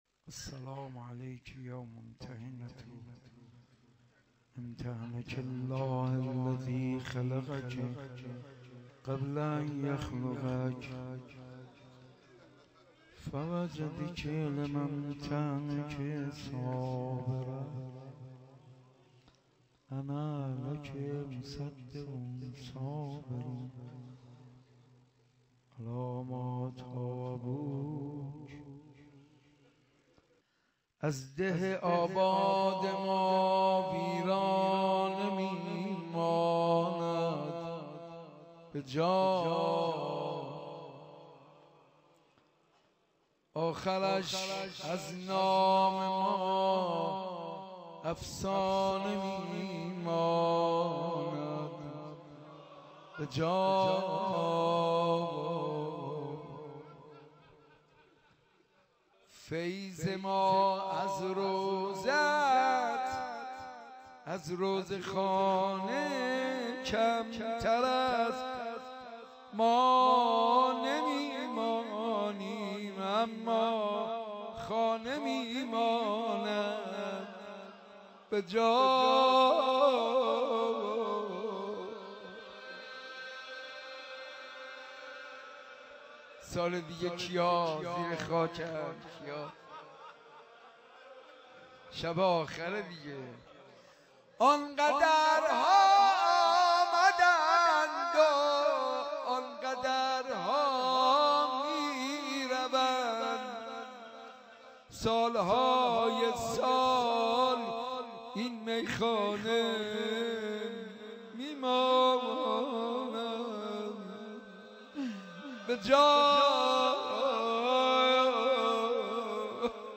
مجلس کامل
مناسبت : دهه اول صفر